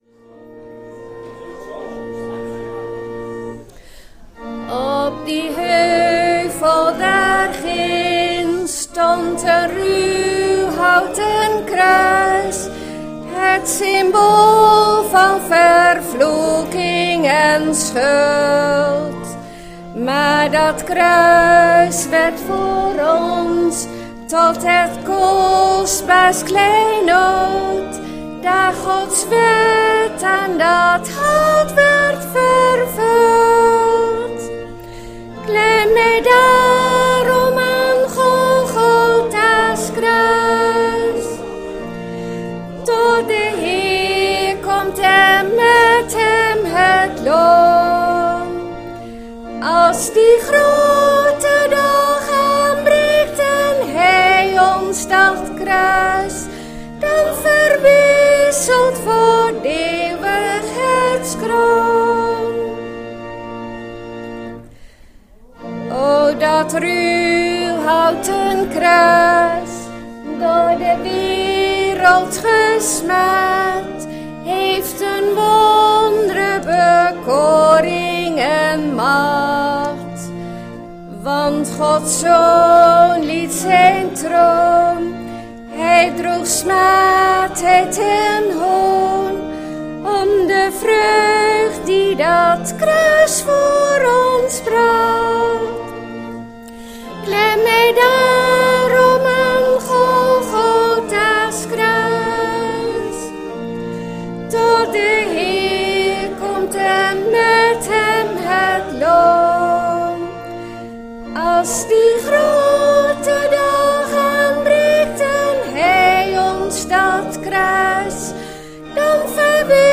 Label: schriftlezing Psalmen 22 vs 1 -22